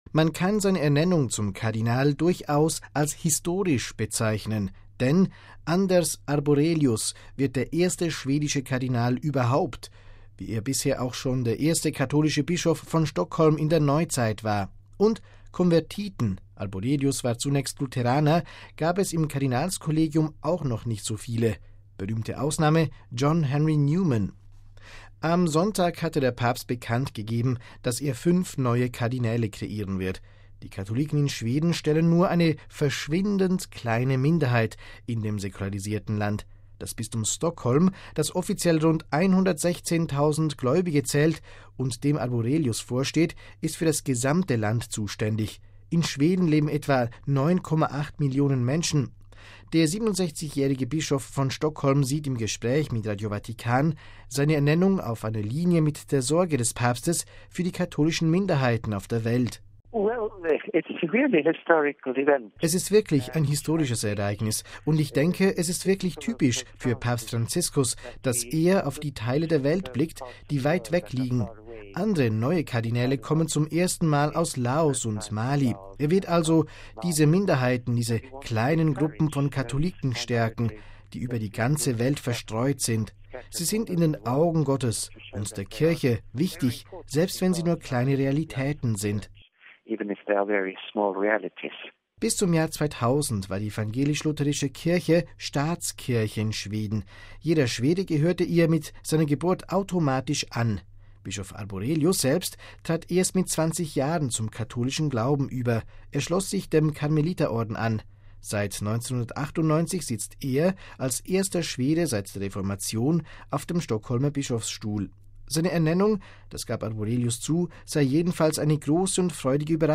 Der 67-jährige Bischof von Stockholm sieht im Gespräch mit Radio Vatikan seine Ernennung auf einer Linie mit der Sorge des Papstes für die katholischen Minderheiten auf der Welt: